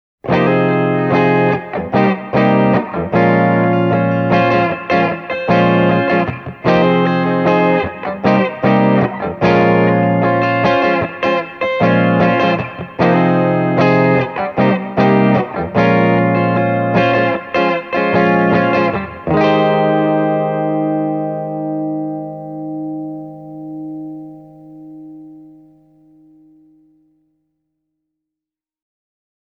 With clean settings Channel 1 will give you a fuller mid-range compared to the more Fender-like, chimey Channel 2.
Here’s what Channel 1 sounds like played clean with an Epiphone Casino (first clip) and a Gibson Melody Maker SG (second clip):